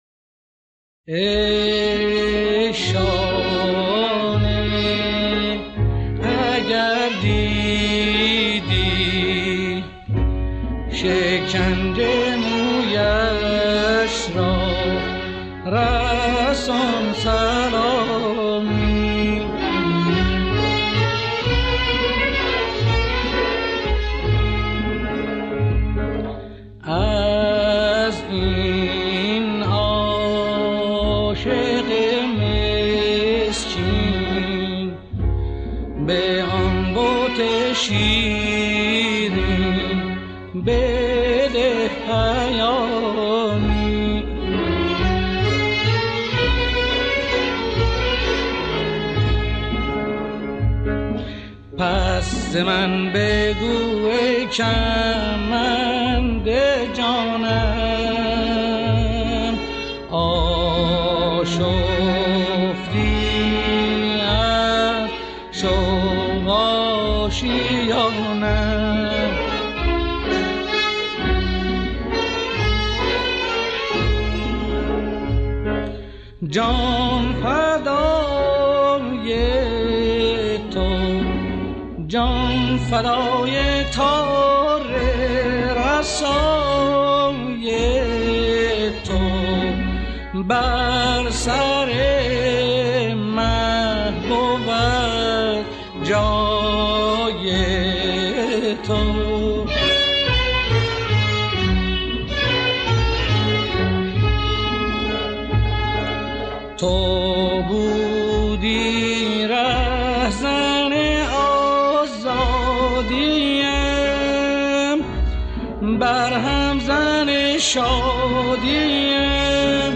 در مقام سه گاه